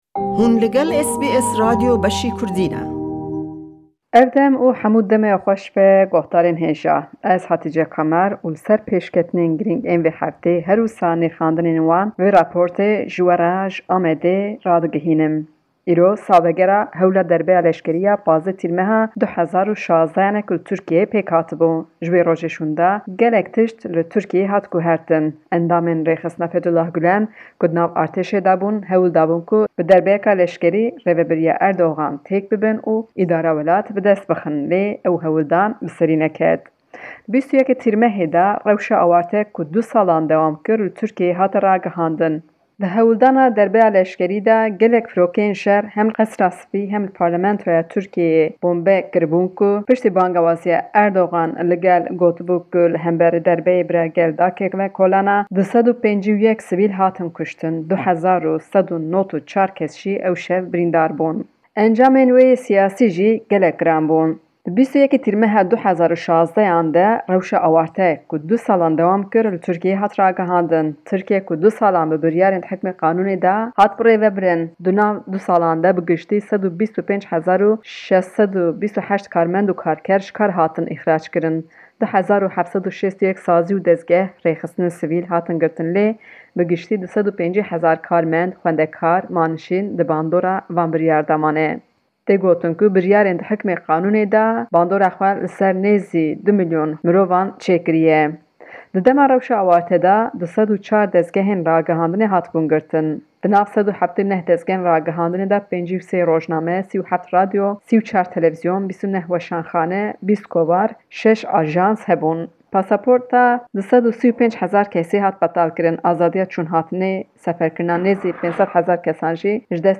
report from Diyarbakir